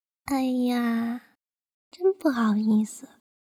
害羞.wav
害羞.wav 0:00.00 0:03.54 害羞.wav WAV · 305 KB · 單聲道 (1ch) 下载文件 本站所有音效均采用 CC0 授权 ，可免费用于商业与个人项目，无需署名。
人声采集素材/人物休闲/害羞.wav